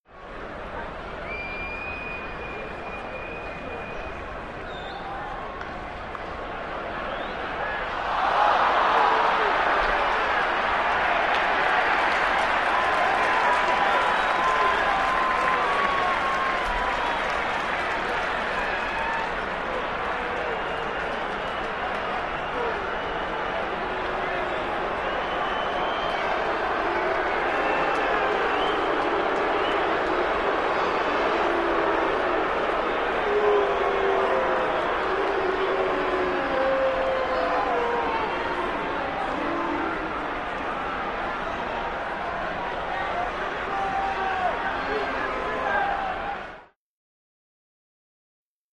Arena Crowd; Crowd, Ohhs To Boos With Light Clapping.